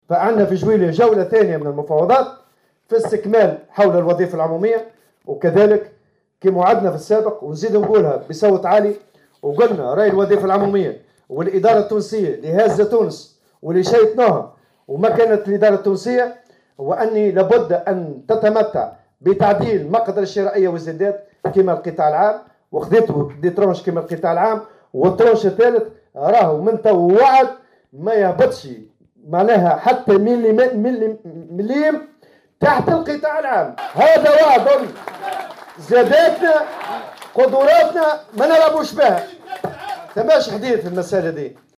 وأكد الطبوبي في تصريح لمراسلة الجوهرة اف ام خلال إشرافه على المؤتمر 18 للفلاحة بالحمامات اليوم الجمعة، أن قيمة القسط الثالث للوظيفة العمومية ستكون مثل القطاع العام تماما، فهو وعد قطعه على نفسه، على حد تعبيره.